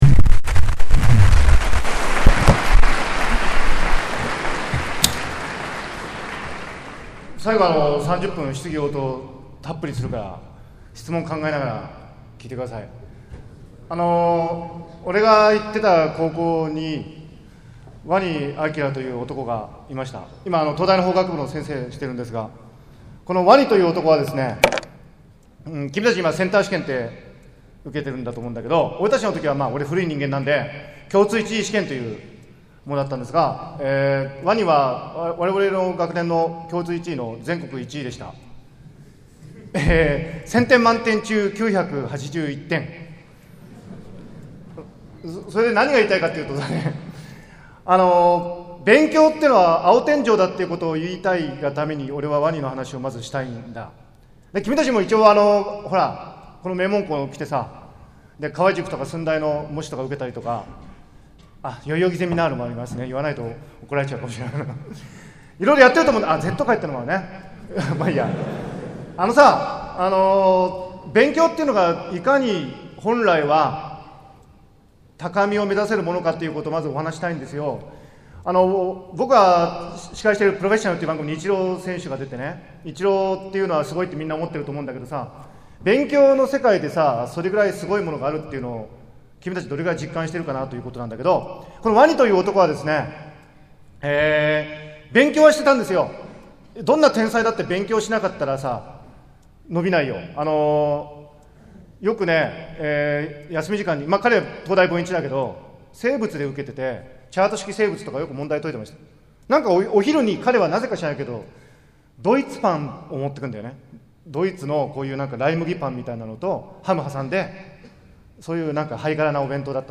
埼玉県立浦和高校講演 学問は青天井
2008-10-24 学問は青天井 レクチャーと質疑応答 「学生たちを前に、思い切りアジった。」